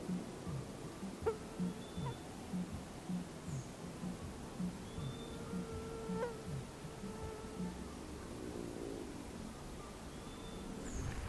Chivi Vireo (Vireo chivi)
Life Stage: Adult
Location or protected area: Parque Provincial Araucaria
Condition: Wild
Certainty: Recorded vocal